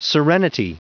Prononciation du mot serenity en anglais (fichier audio)
Prononciation du mot : serenity